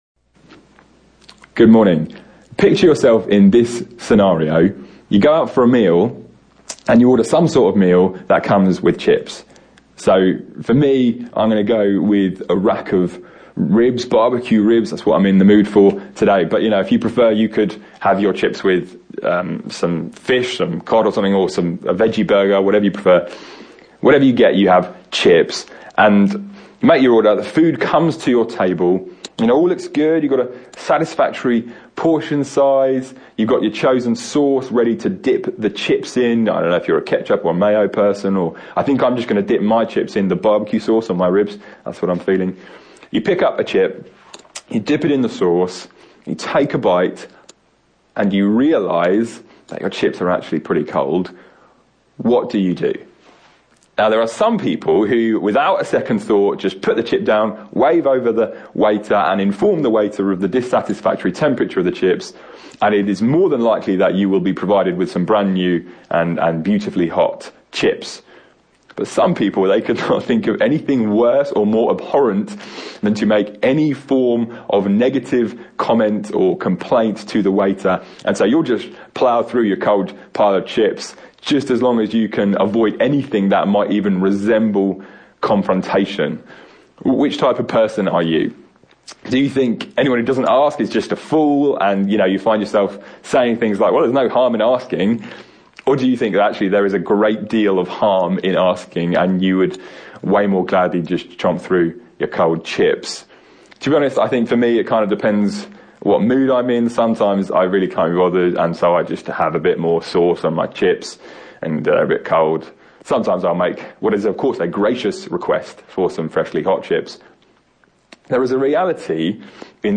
Series: Miscellaneous Sermons 2019